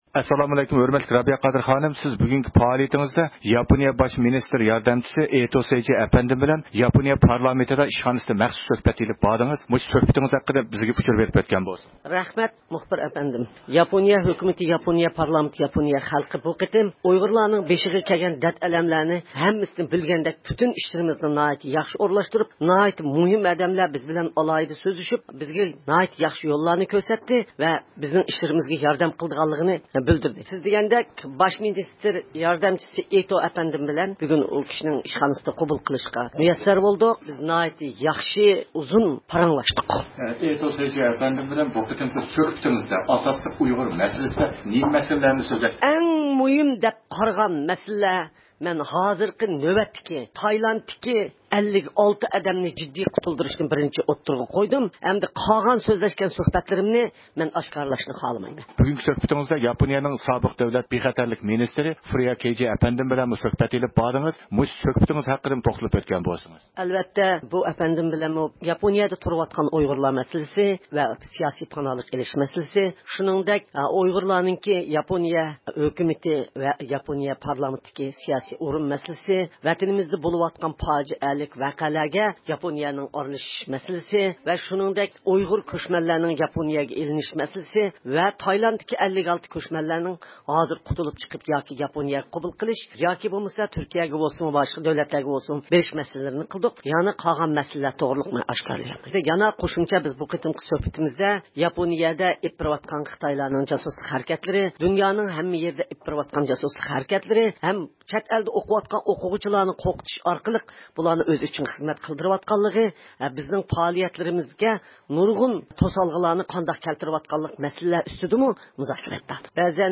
بىز رابىيە قادىر خانىمنىڭ ياپونىيە پارلامېنتىدىكى بىر قاتار پائالىيەتلىرى ۋە دوكلاتىدىن كېيىن، خانىمنىڭ بۈگۇن ئېلىپ بارغان يېپىق ئۇچىرىشىشلىرى توغرىسىدا سۆھبەت ئېلىپ باردۇق.